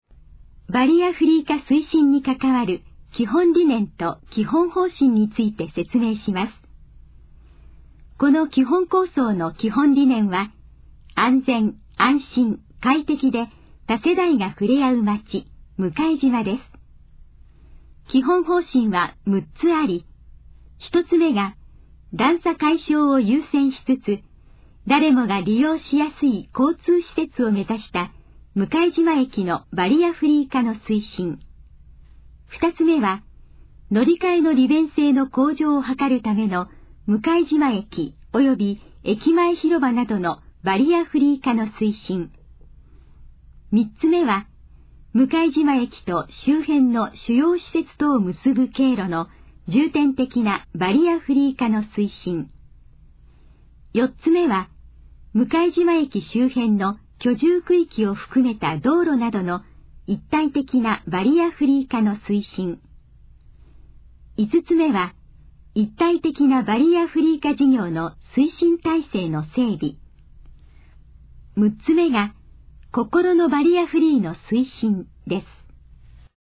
このページの要約を音声で読み上げます。
ナレーション再生 約154KB